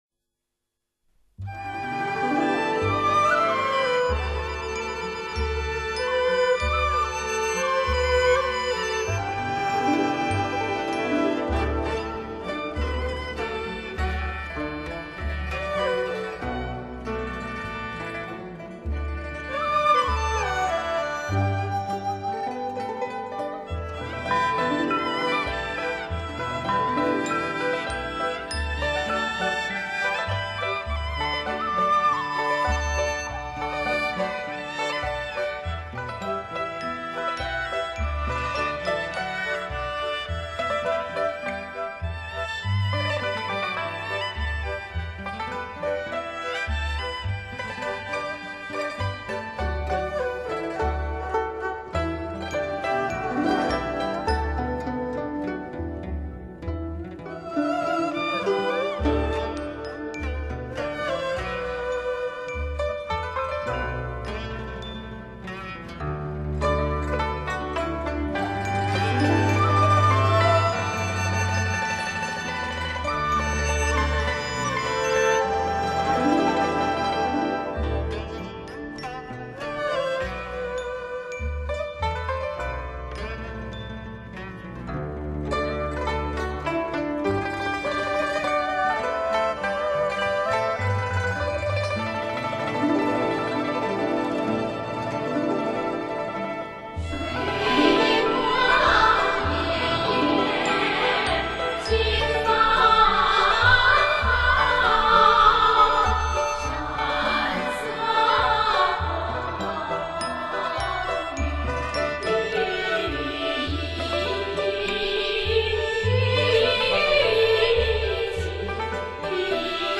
民乐合奏专辑
具有浓郁的江南地方色彩。
曲调通俗易上口，具有越剧风味。